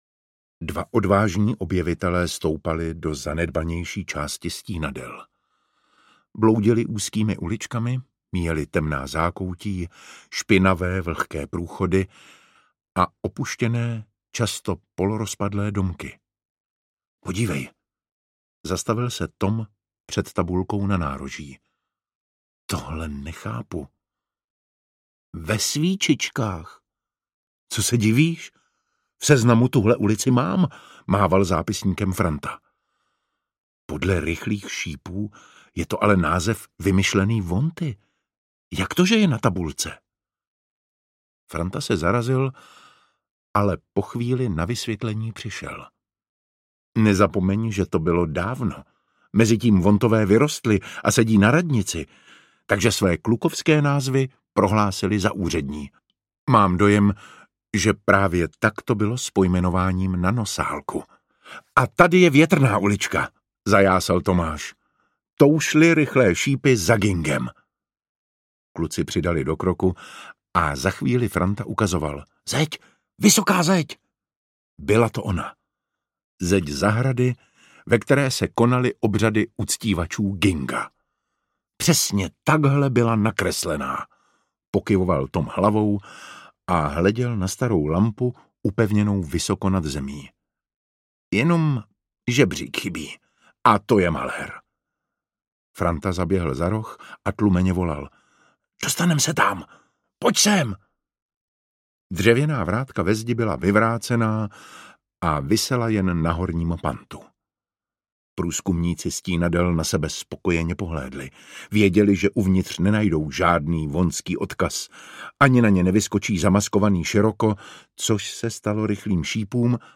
Ukázka z knihy
Čte David Matásek.
Vyrobilo studio Soundguru.